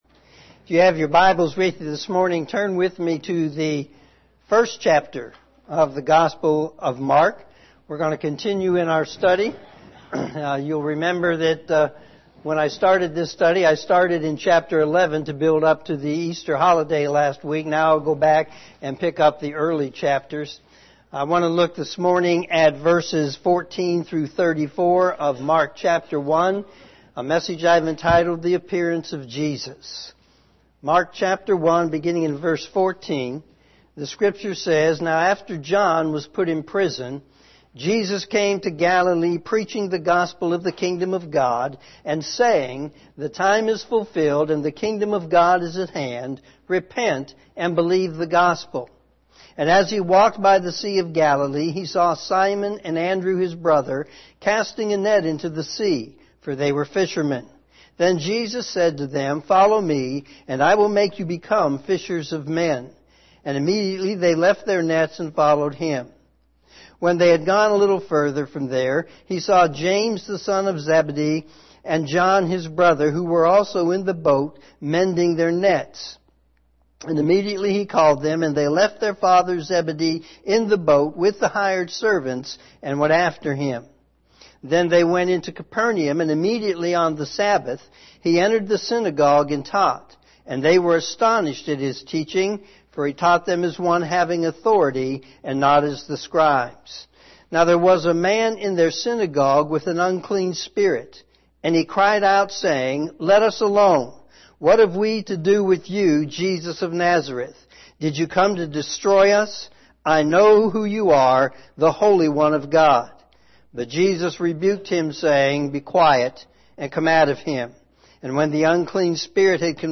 sermon4-28-19am.mp3